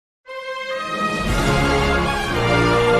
Free Foley sound effect: Spray Can.
Spray Can
# spray # can # aerosol # hiss About this sound Spray Can is a free foley sound effect available for download in MP3 format.
520_spray_can.mp3